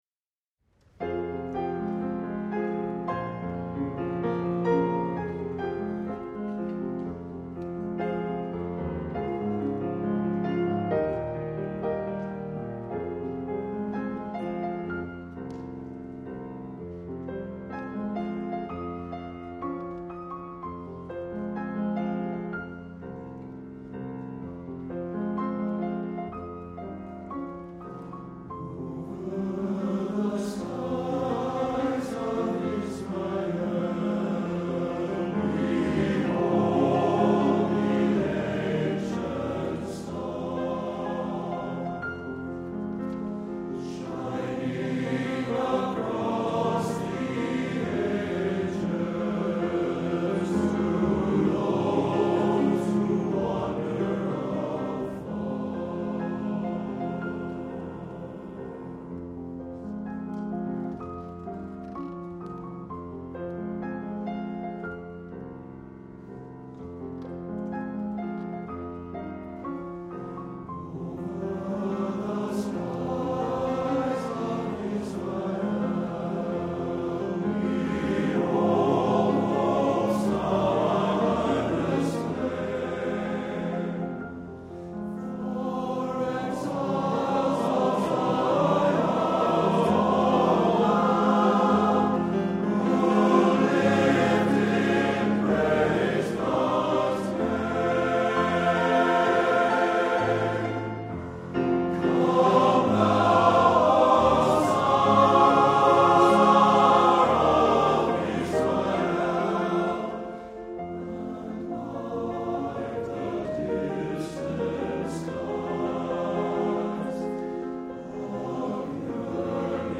Fall 2010 — Minnesota Valley Men's Chorale